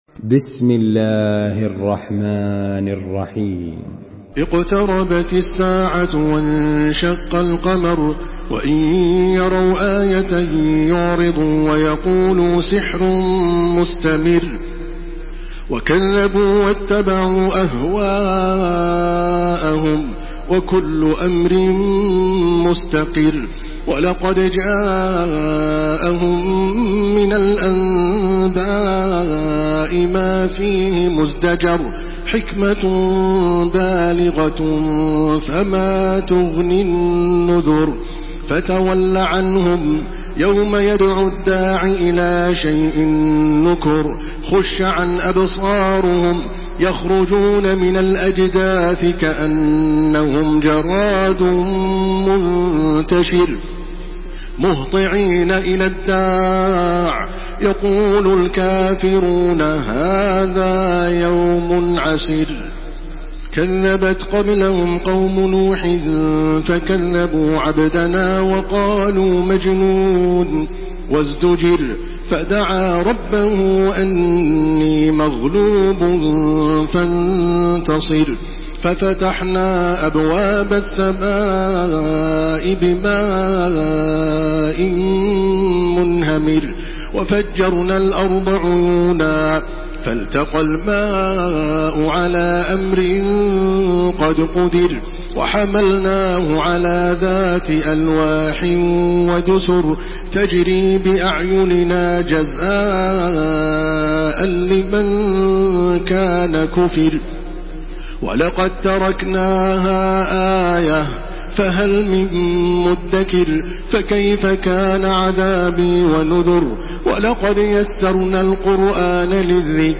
تحميل سورة القمر بصوت تراويح الحرم المكي 1429
مرتل حفص عن عاصم